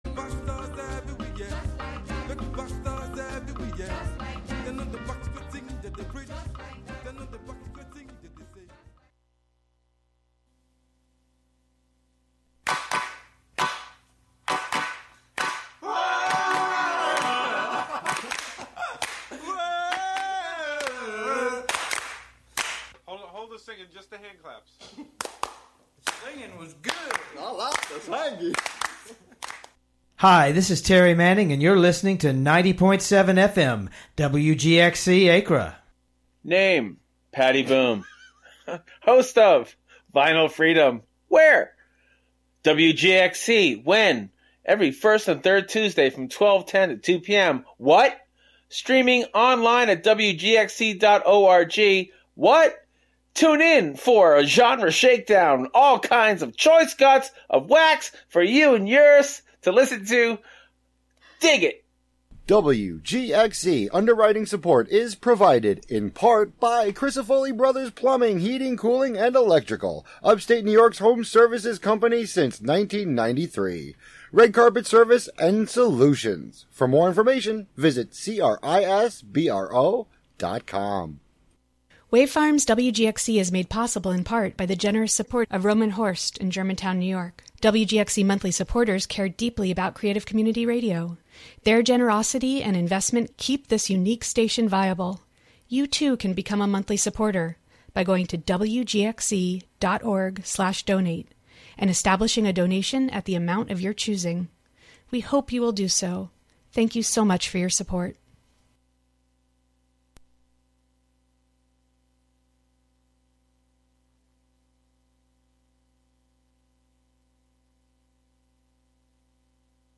four feral post-punk albums